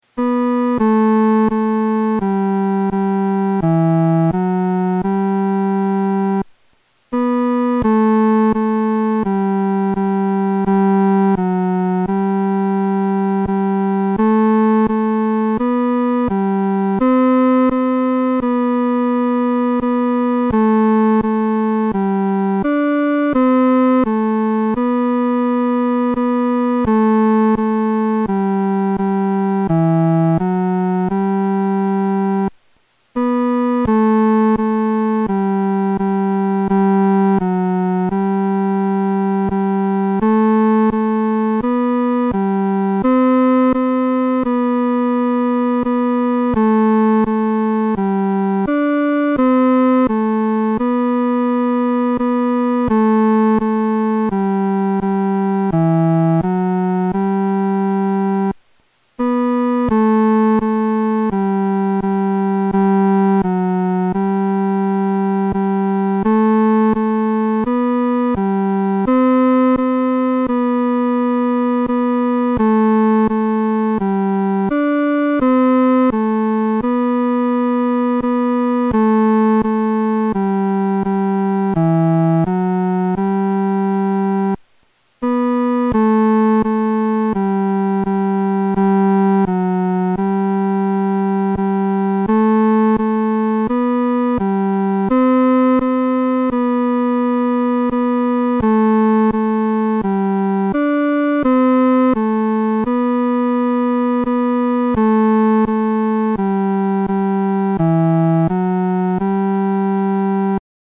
男高
这首诗歌充满着虔敬和恳切，我们在弹唱时的速度不宜太快。